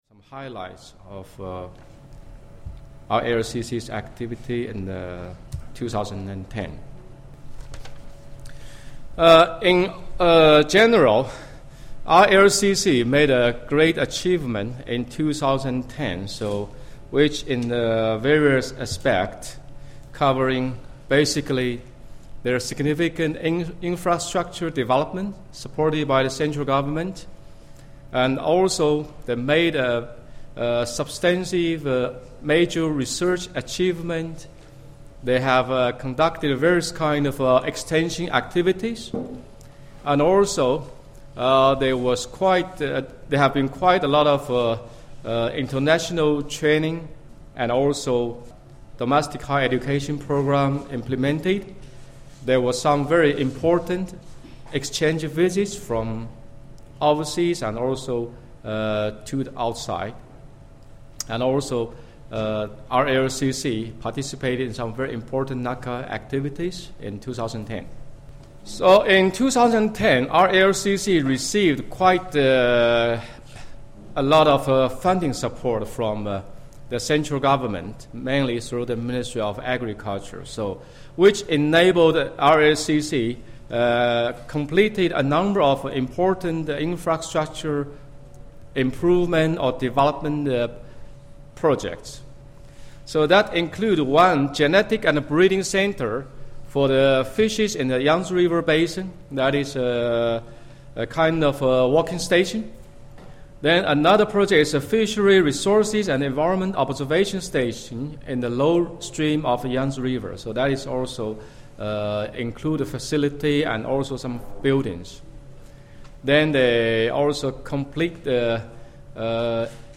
The presentations were made at the 22nd NACA Governing Council Meeting in Cochi, Kerala, from 9-11 May 2011, India.